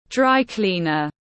Cửa hàng giặt khô tiếng anh gọi là dry cleaner, phiên âm tiếng anh đọc là /ˌdraɪˈkliː.nəz/.
Dry cleaner /ˌdraɪˈkliː.nəz/
Dry-cleaner.mp3